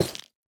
Minecraft Version Minecraft Version 1.21.5 Latest Release | Latest Snapshot 1.21.5 / assets / minecraft / sounds / block / nether_wood_hanging_sign / break3.ogg Compare With Compare With Latest Release | Latest Snapshot
break3.ogg